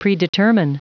Prononciation du mot predetermine en anglais (fichier audio)
Prononciation du mot : predetermine